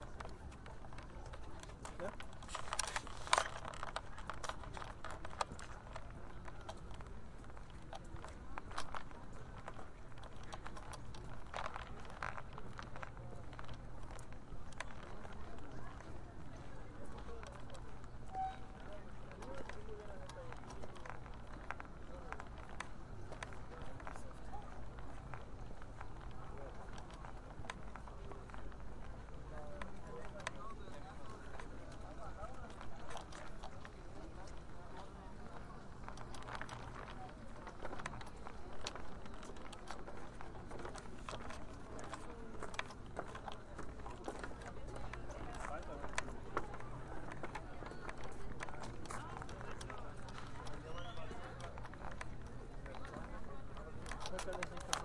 第2步 " 浴室
描述：周围
标签： 环境 背景 背景声 声景
声道立体声